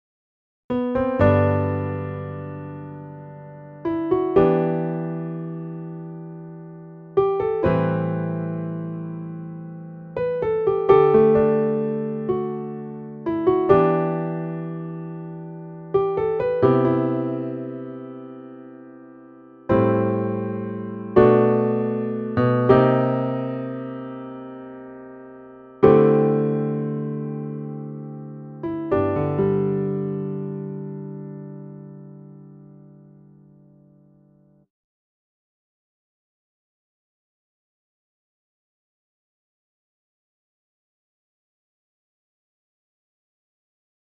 ピアノ曲